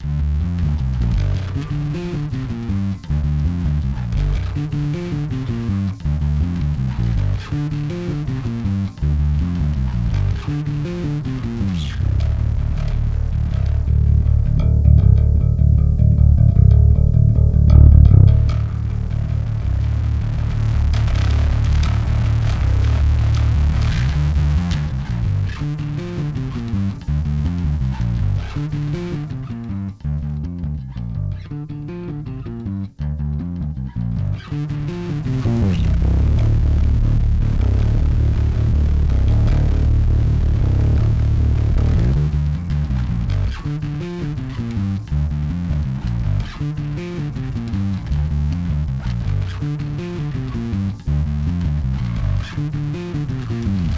Pedal WALDMAN para Baixo, Bass Classic Overdrive Mod. BCO-2, Linha Stage FX
Pedal-BCO-2.wav